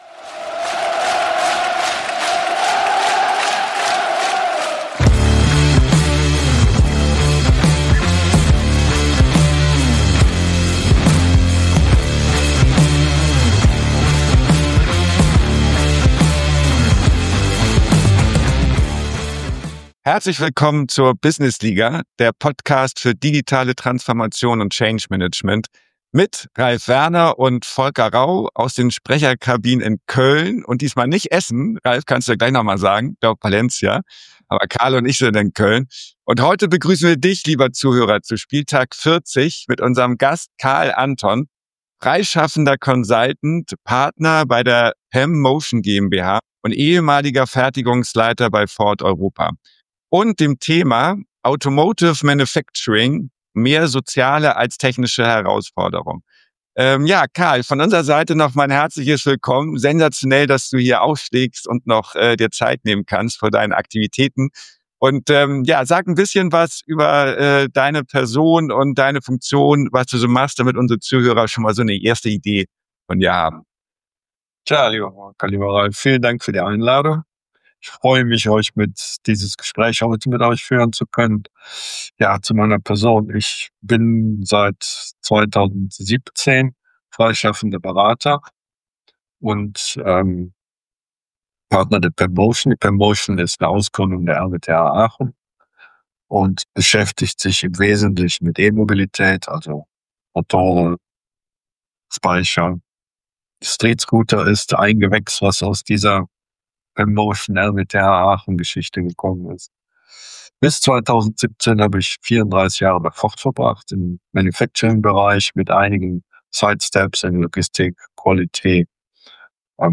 Interviewzone